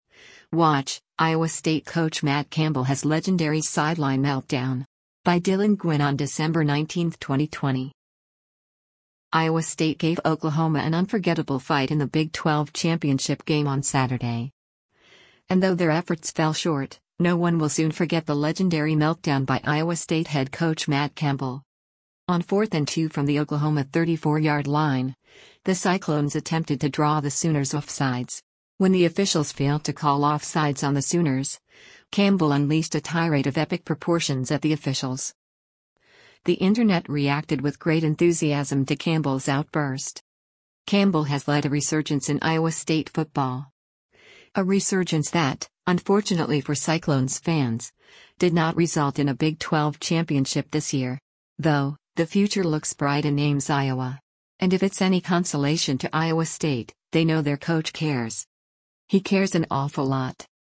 WATCH: Iowa State Coach Matt Campbell Has Legendary Sideline Meltdown
On 4th & 2 from the Oklahoma 34-yard line, the Cyclones attempted to draw the Sooners offsides. When the officials failed to call offsides on the Sooners, Campbell unleashed a tirade of epic proportions at the officials.